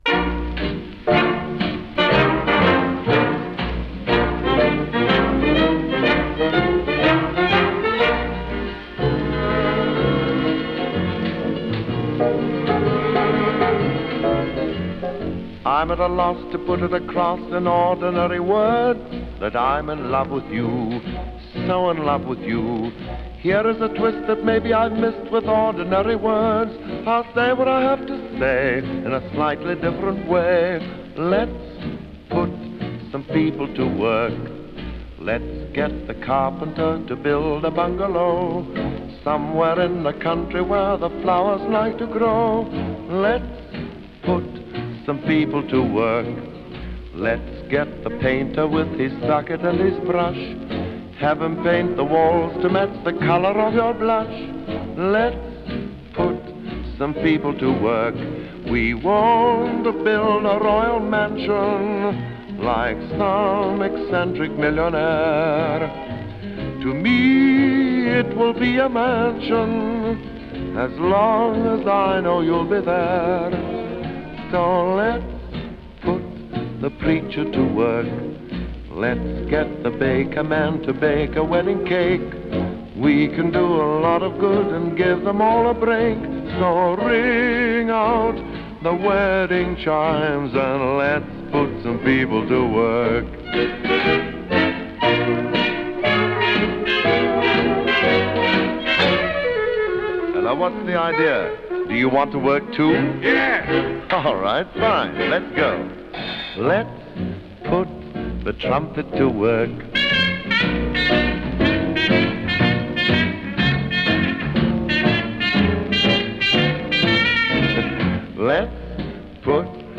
from the 1936 film